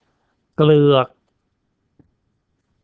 เกลือก  gleuuakL